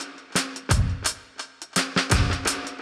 Index of /musicradar/dub-designer-samples/85bpm/Beats
DD_BeatB_85-03.wav